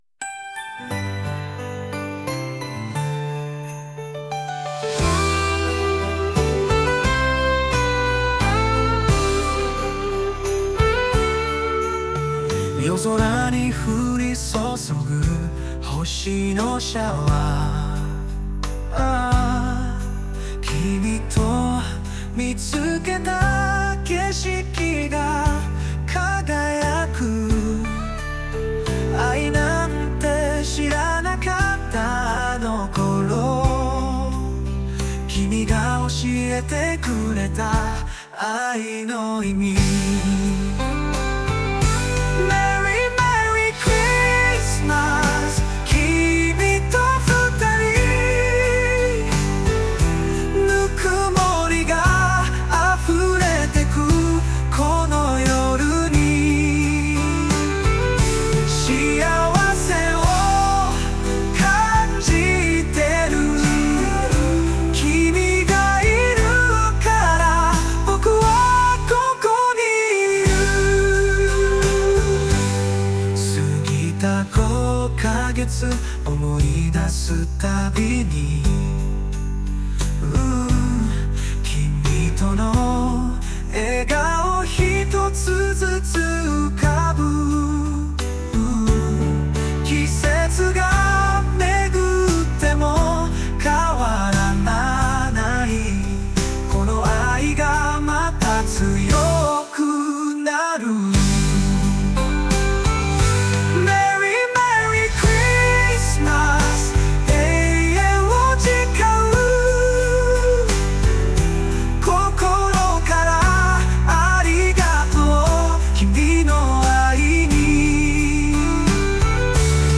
補作詩：GPT-4o